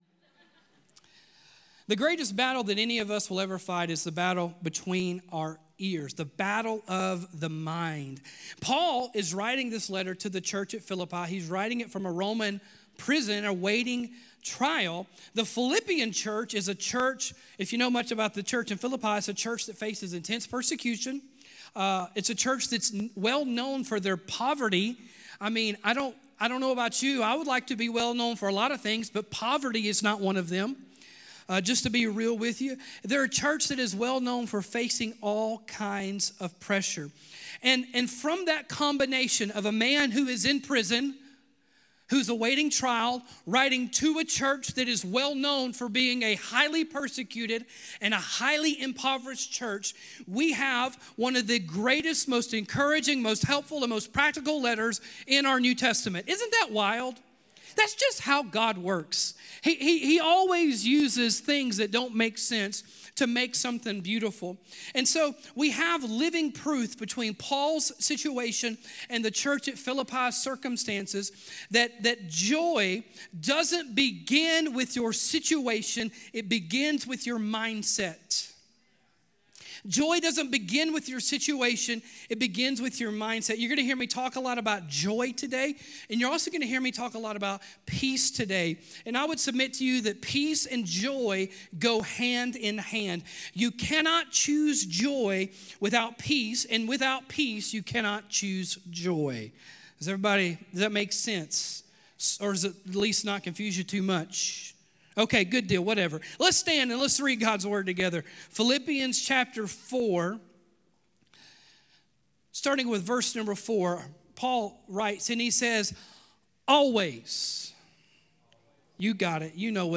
Mind Over Mood | The Fight For Joy | 10.26.25 | LifeHouse Church